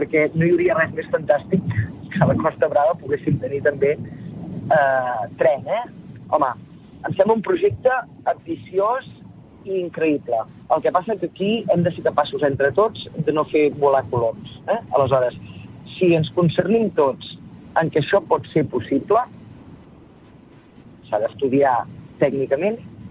Entrevistes SupermatíNotícies
Al Supermatí d’avui, seguint amb les entrevistes dels candidats del 14-F, hem parlat amb Teresa Jordà d’Esquerra Republicana.